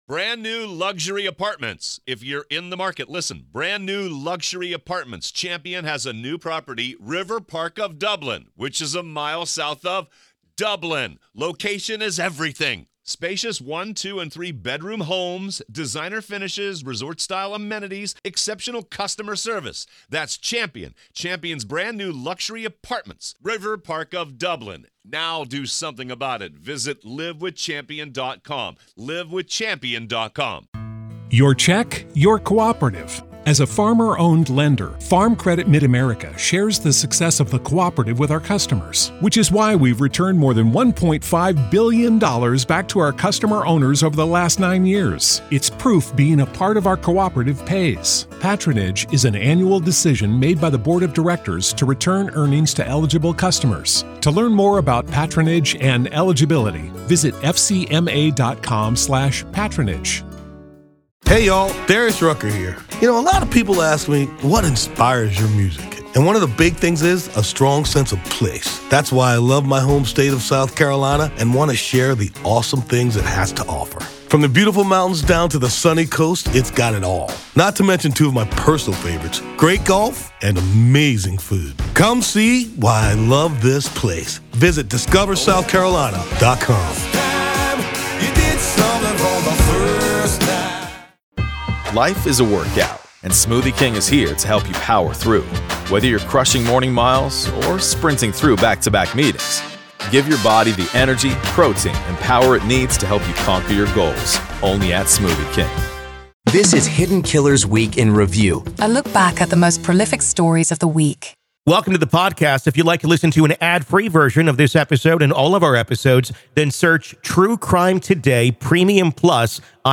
Welcome to the "Week in Review," where we delve into the true stories behind this week's headlines.